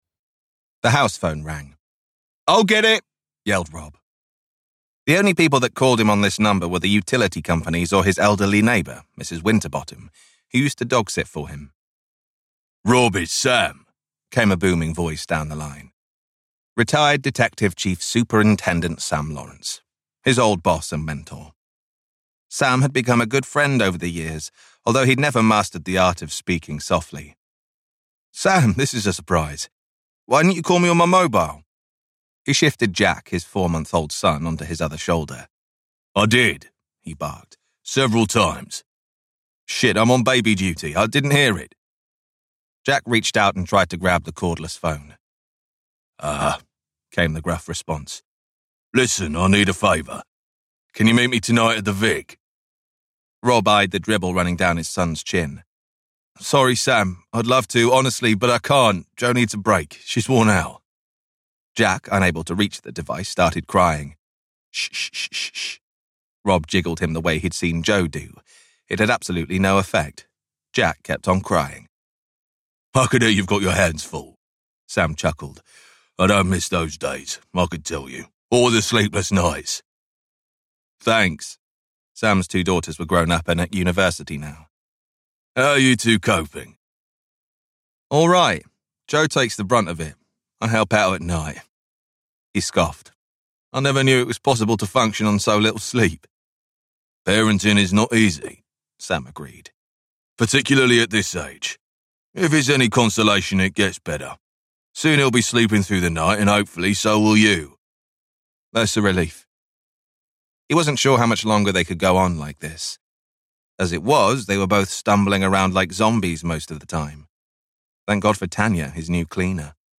The South Bank Murders - Vibrance Press Audiobooks - Vibrance Press Audiobooks